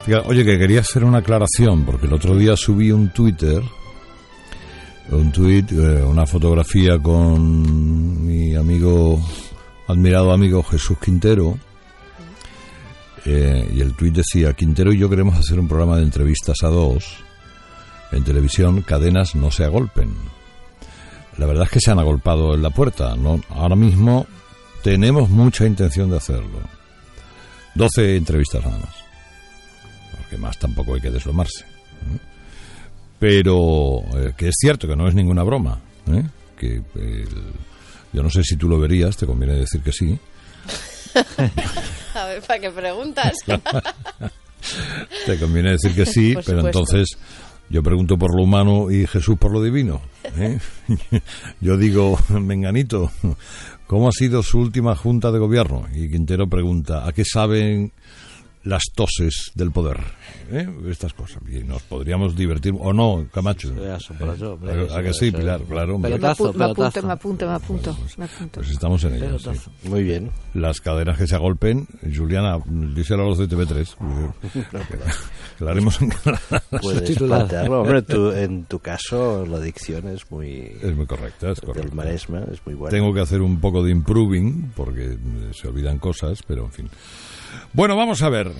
Ante la avalancha de reacciones, aclara en Herrera en la onda que "es cierto y que tenemos mucha intención de hacerlo".